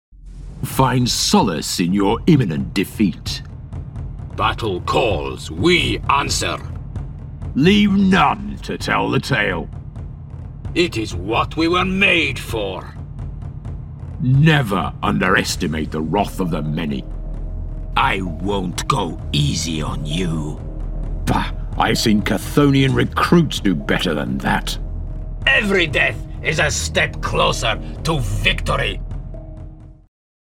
Male
Video Games
Warhammer Voices
Words that describe my voice are Conversational, Natural, Versatile.